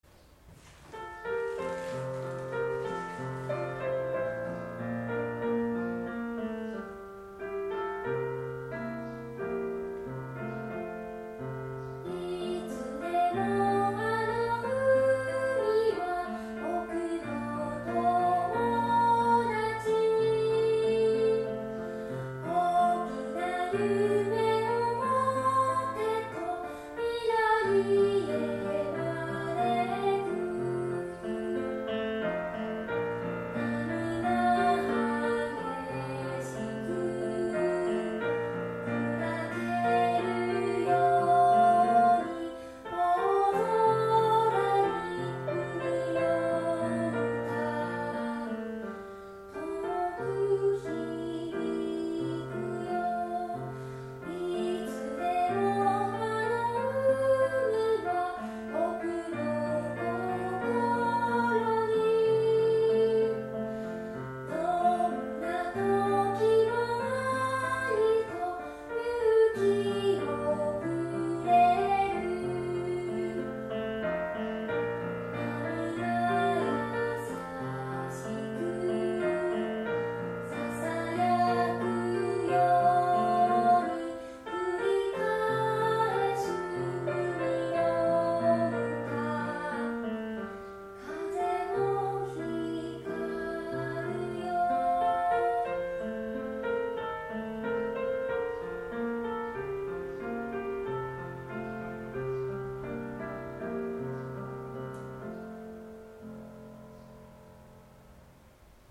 5年 音楽科「いつでもあの海は」
2パートをよく聴いて、歌ってみましょう。